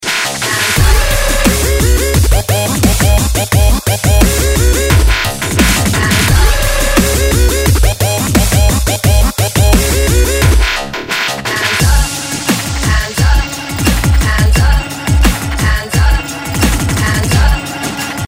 Dubstep рингтоны